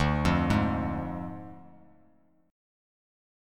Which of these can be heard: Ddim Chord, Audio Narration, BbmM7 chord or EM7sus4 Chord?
Ddim Chord